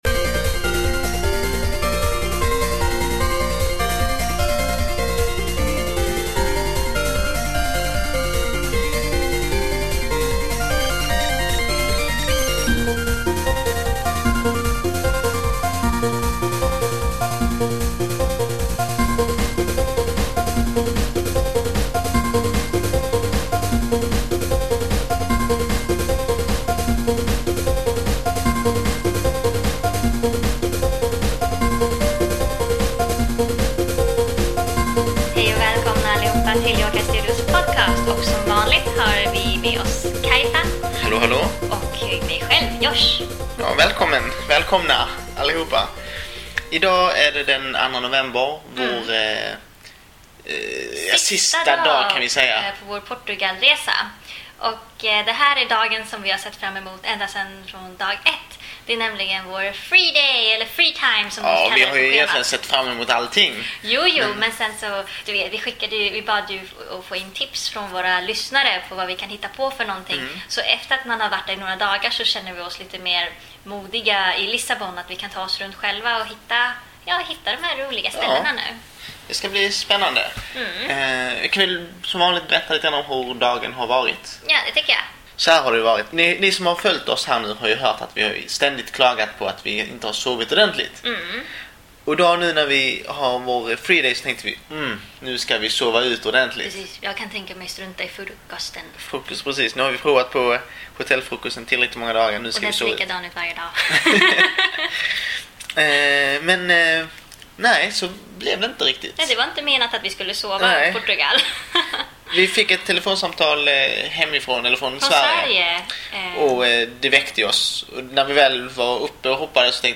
The staff of Yokaj Studio, Sweden's first and most established manga studio, discuss news and give you updates about the studio, the Swedish manga market, bits and bobs of the manga culture in Sweden and more!